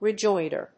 発音記号
• / rɪdʒˈɔɪndɚ(米国英語)
• / rɪˈdʒɔɪndɜ:(英国英語)